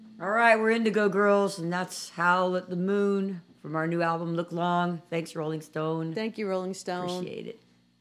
(captured from the video webcast)
05. talking with the crowd (0:08)